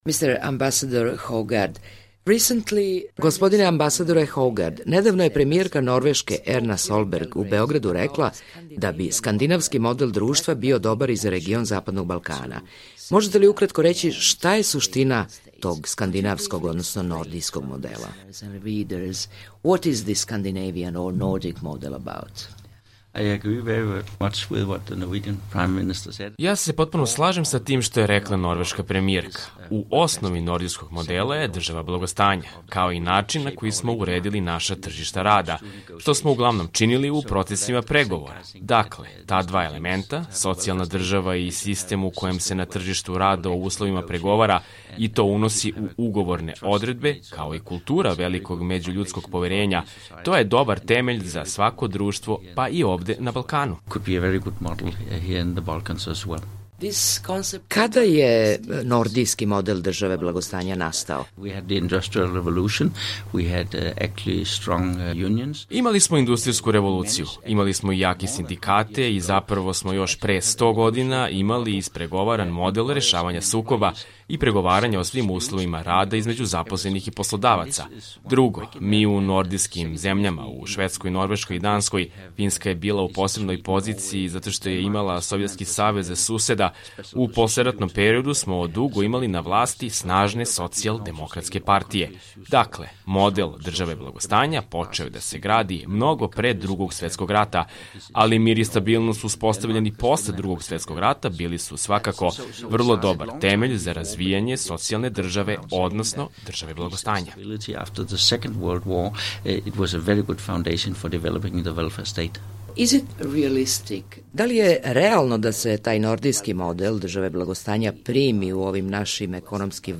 Intervju nedelje